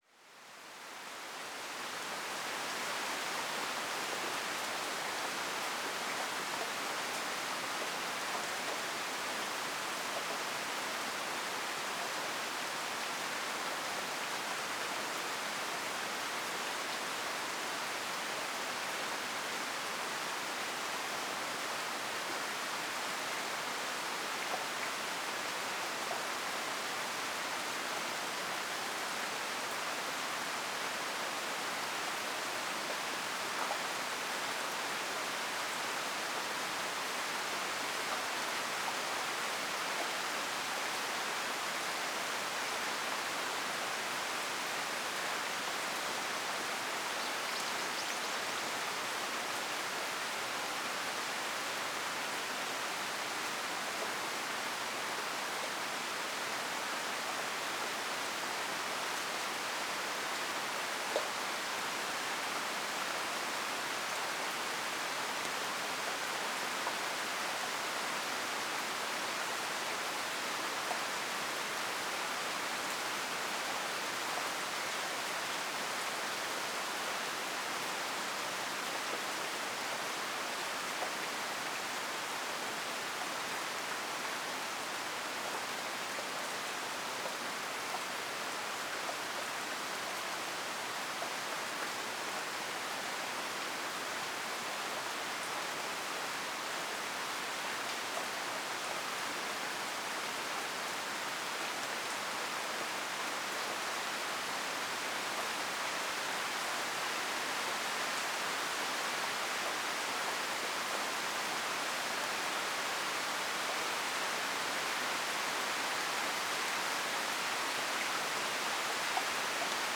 雨 - 久米島・白瀬川 07:49am